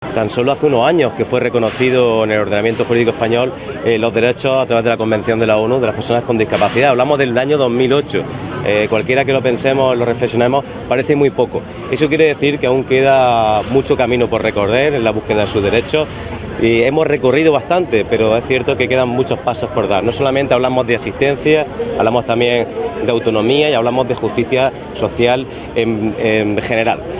El acto se ha desarrollado en la Avenida Federico García Lorca de la capital y ha contado con el conjunto de entidades de Almería que representan a las personas con discapacidad.
TOTAL-FRANCISCO-BELLIDO-Delegado-Bienestar-Social.wav